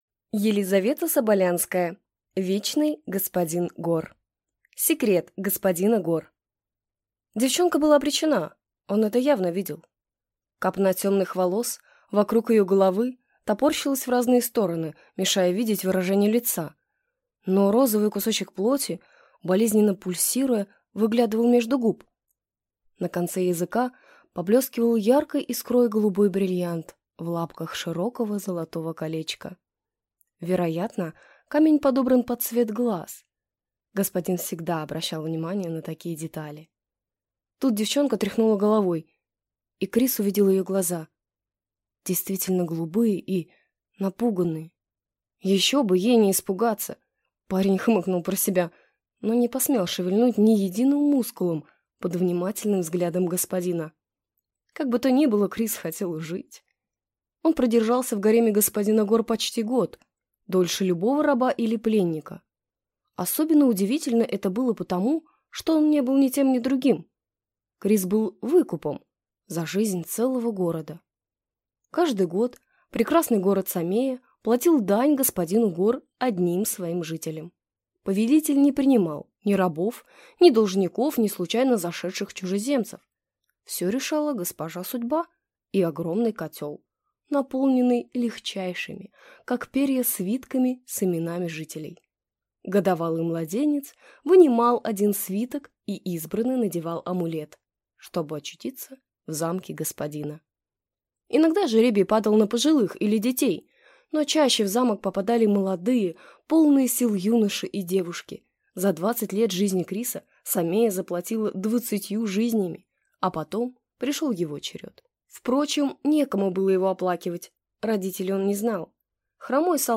Аудиокнига Вечный Господин Гор | Библиотека аудиокниг